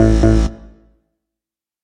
Звуки полиграфа
Звук ложного ответа на тесте правды